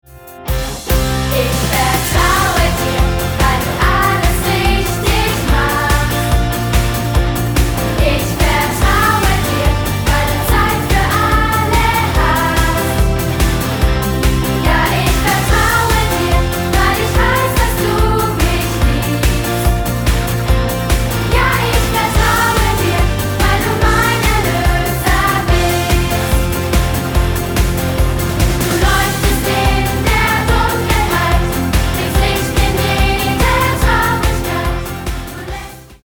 Musical-CD